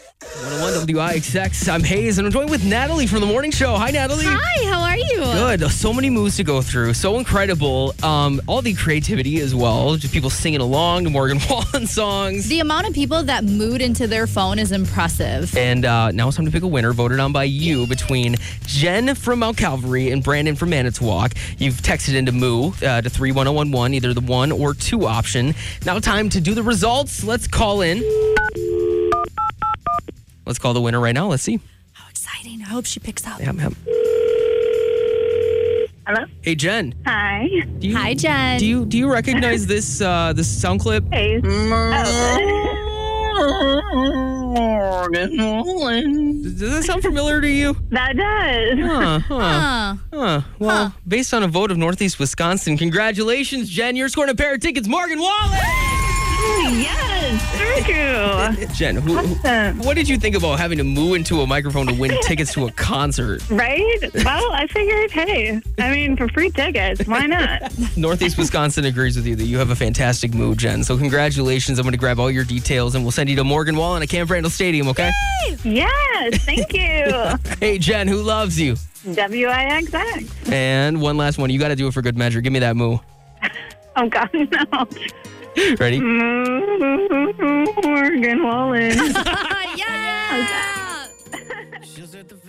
Hear the winning MOO!
wixx-mooformorgan-winner2025.mp3